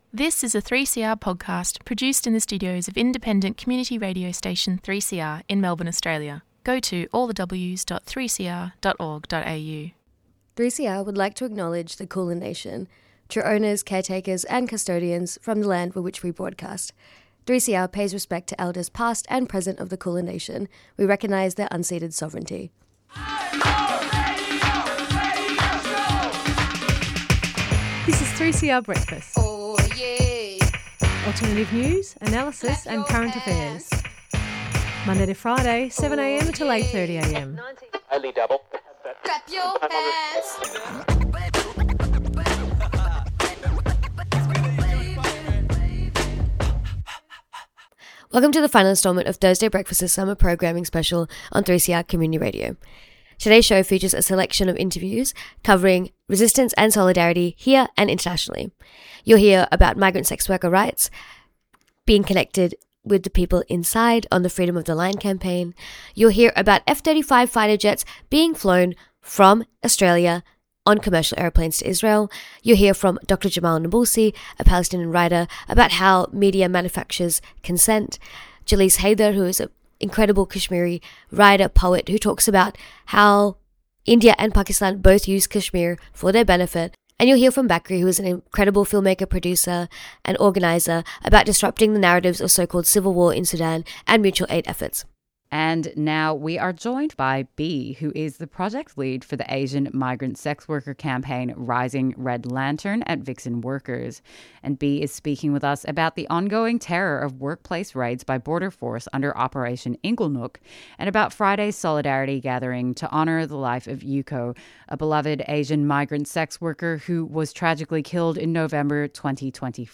Acknowledgement of Country// Headlines//